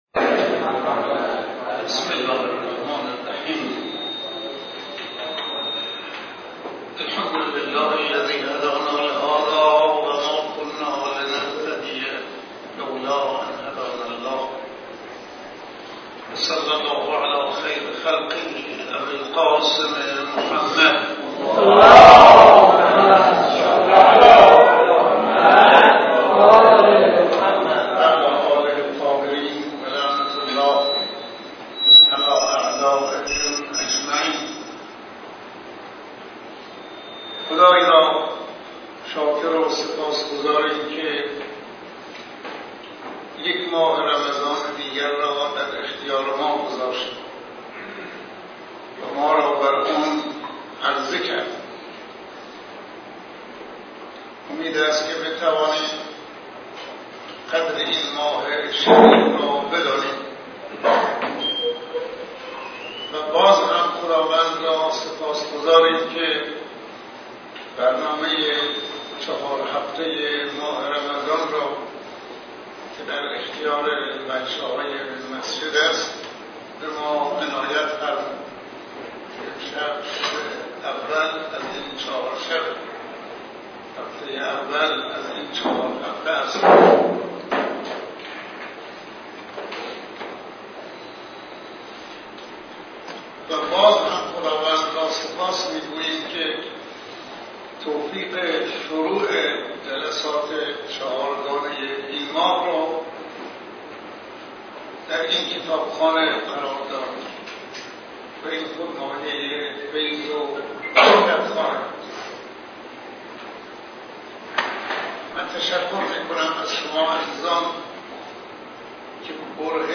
‌‌ سخنرانی حضرت آیت الله شفیعی جلسه اول: اخلاص